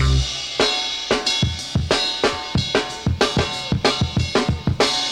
• 124 Bpm Drum Loop Sample C Key.wav
Free drum loop - kick tuned to the C note. Loudest frequency: 1803Hz
124-bpm-drum-loop-sample-c-key-3sk.wav